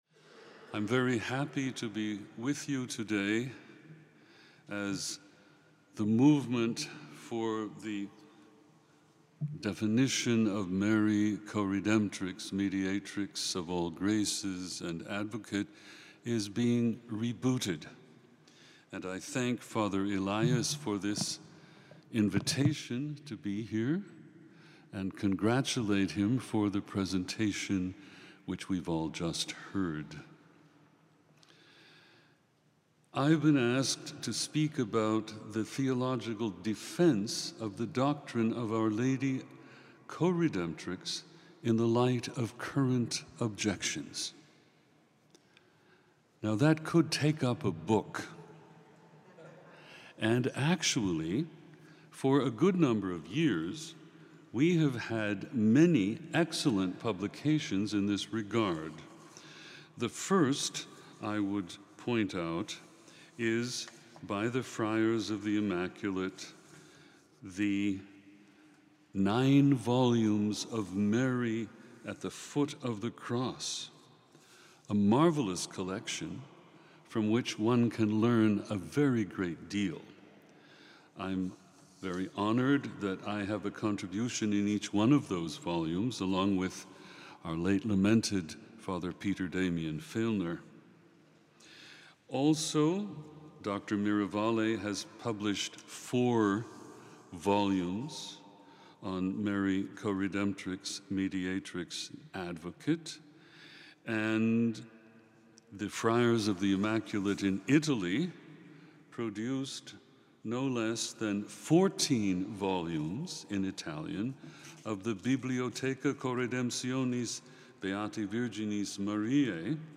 at the Marian Coredemption symposium at the Shrine of Our Lady of Guadalupe, La Crosse, WI in 2018